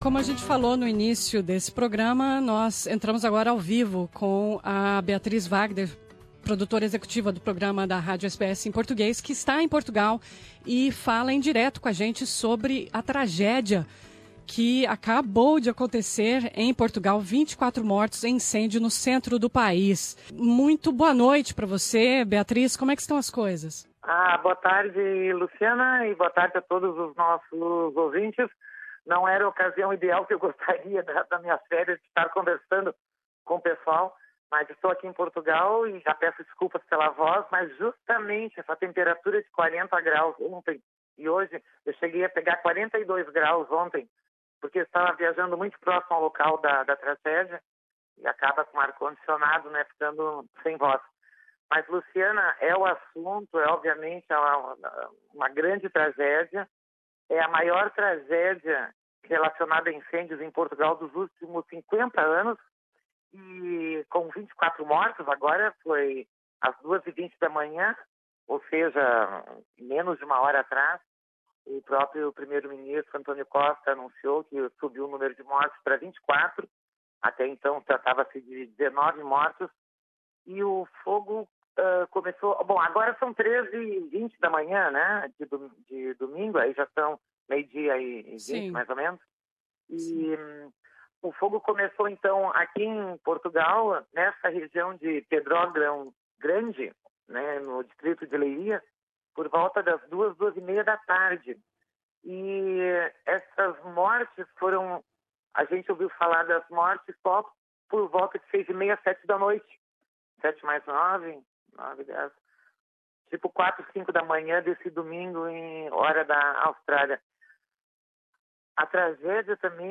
Direto de Portugal